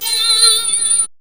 2805L SYN-FX.wav